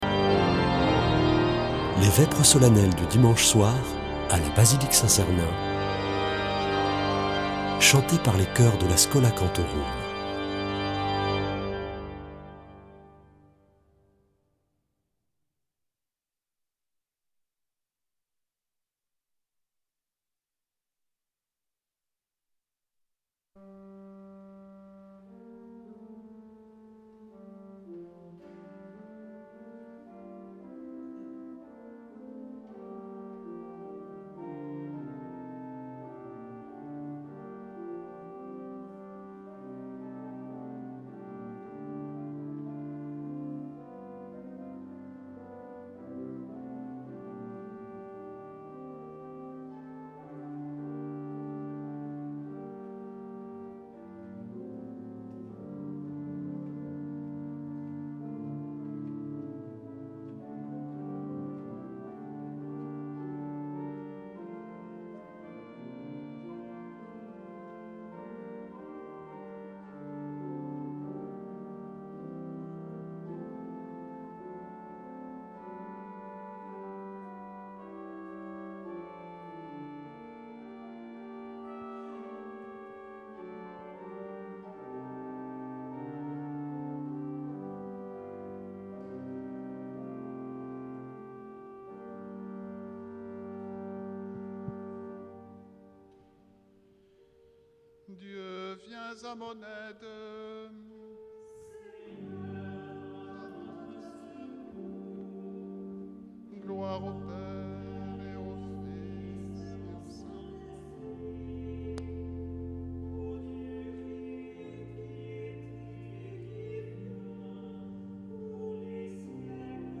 Vêpres de Saint Sernin du 03 nov.
Une émission présentée par Schola Saint Sernin Chanteurs